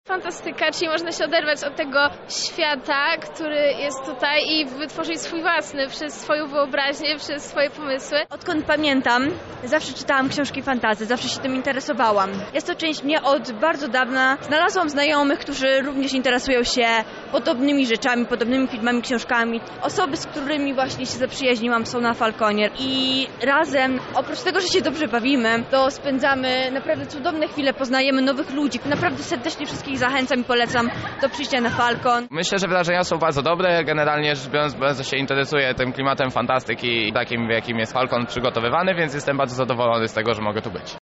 O swoich festiwalowych wrażeniach i miłości do fantastyki opowiadają uczestnicy imprezy.